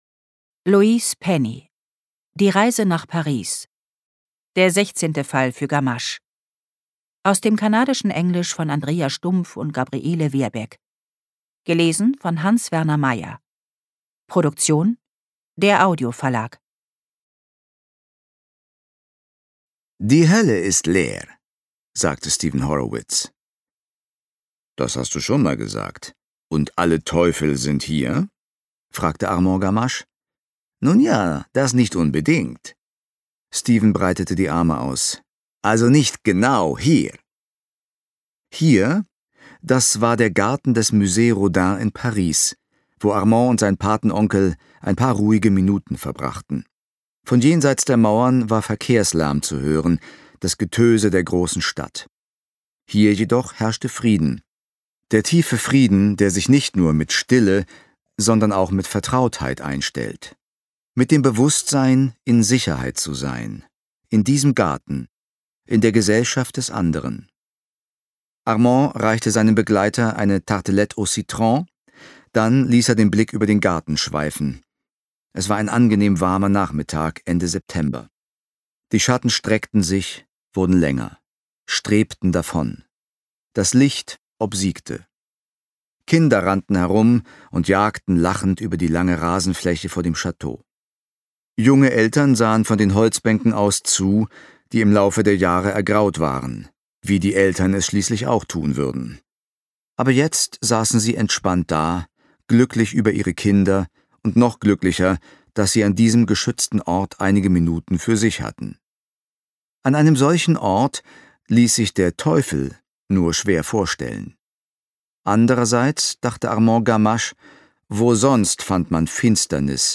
Hans-Werner Meyer (Sprecher)